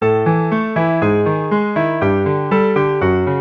This month’s Bardic Song is called “Spirit Song”. It is a simple tune that has 4 lines for easier repetition and the melody line is rather easy. It can be used to call Spirit as you see fit – replacing the word “Spirit” with the name of a Deity will also work.